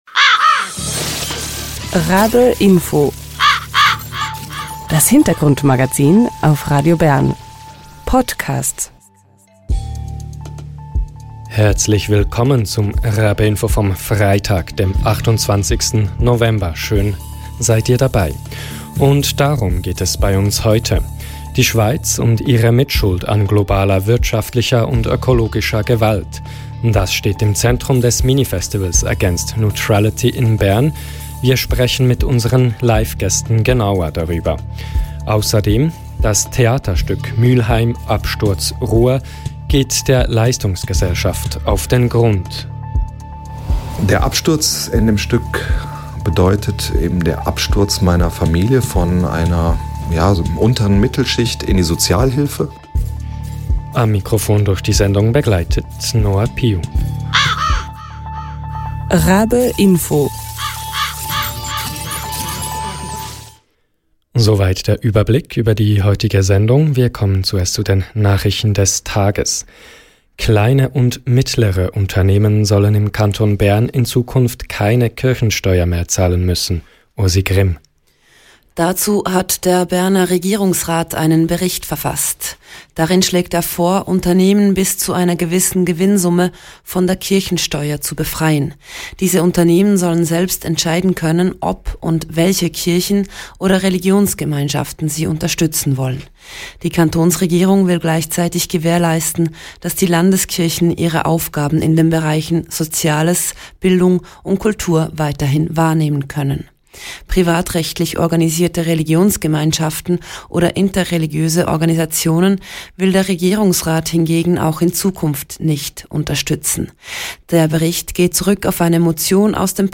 Wir sprechen live im Studio über die Idee dahinter und das Programm.